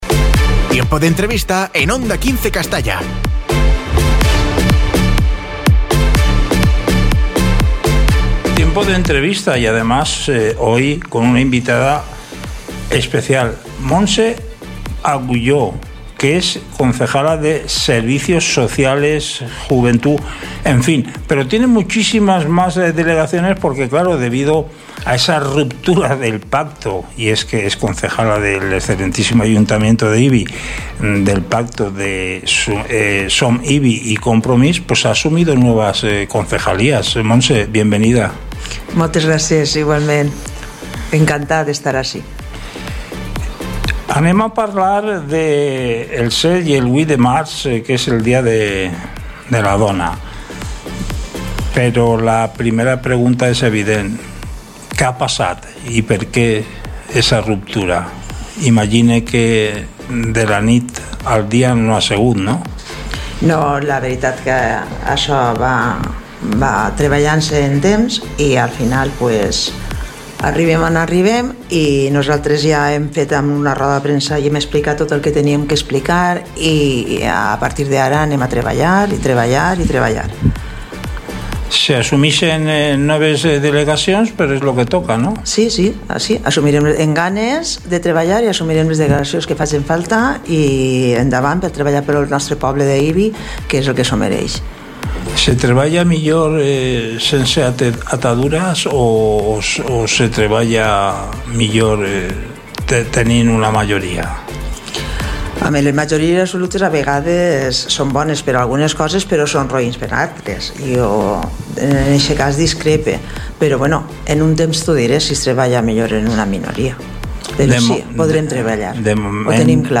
Entrevista a Montse Agulló, Regidora de Serveis Socials de l'Ajuntament d'Ibi - Onda 15 Castalla 106.0 FM